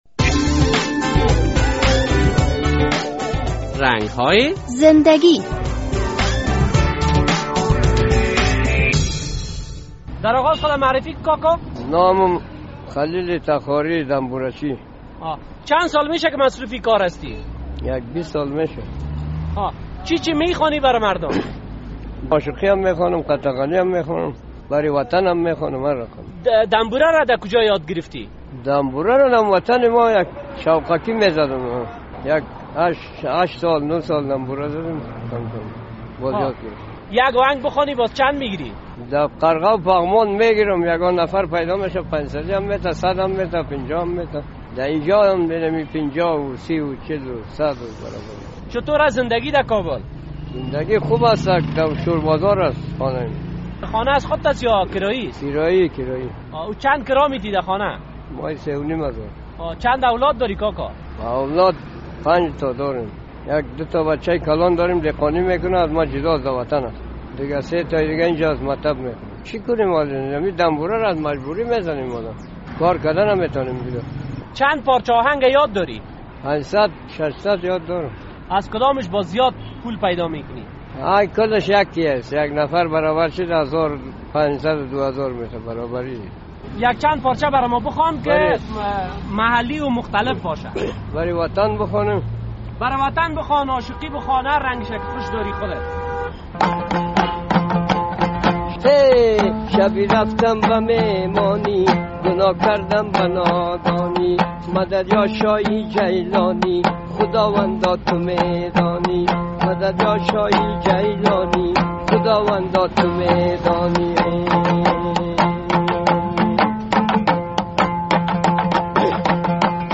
در این برنامهء رنگ‌های زنده‌گی خبرنگار رادیو آزادی با یک دنبوره نواز مصاحبه کرده است...